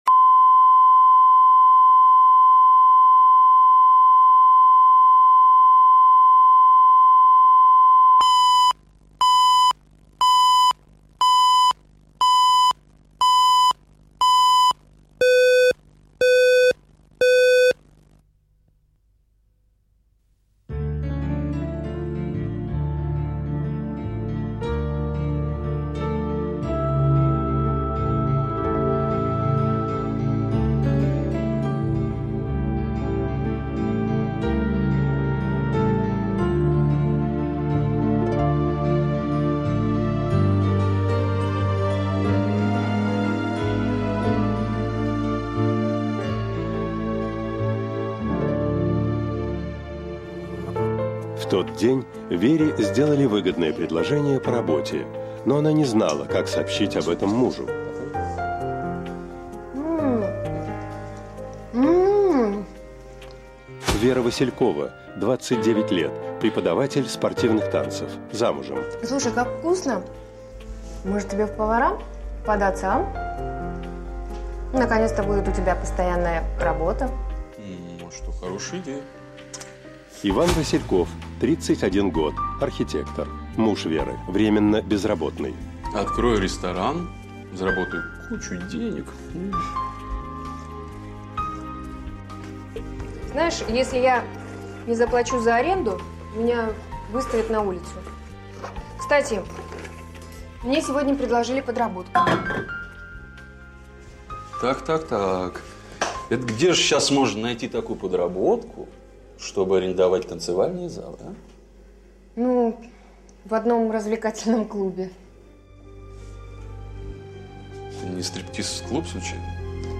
Аудиокнига Что мы наделали | Библиотека аудиокниг
Прослушать и бесплатно скачать фрагмент аудиокниги